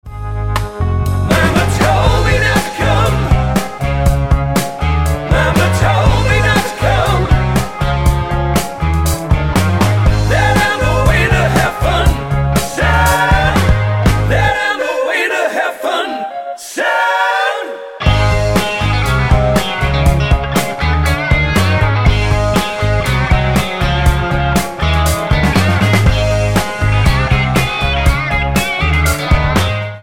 Tonart:Ab mit Chor
Die besten Playbacks Instrumentals und Karaoke Versionen .